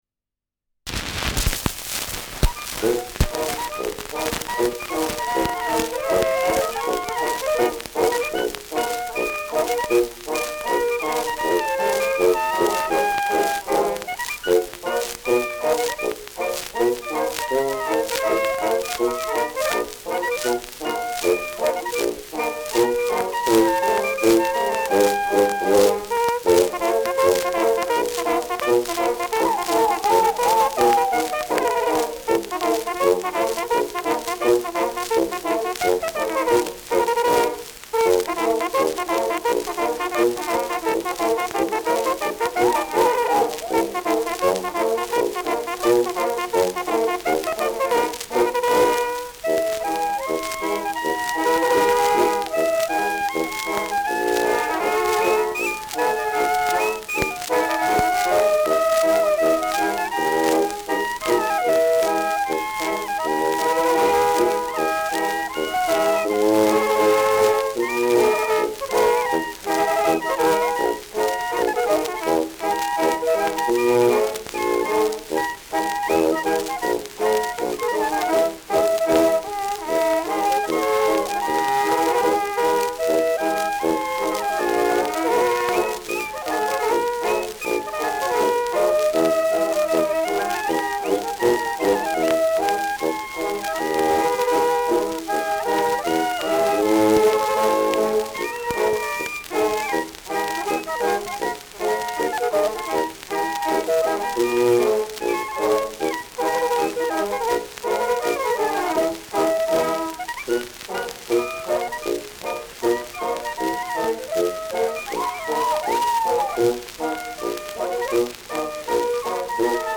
Schellackplatte
Deutlich abgespielt : Häufiges Knacken : Nadelgeräusch
[Nürnberg] (Aufnahmeort)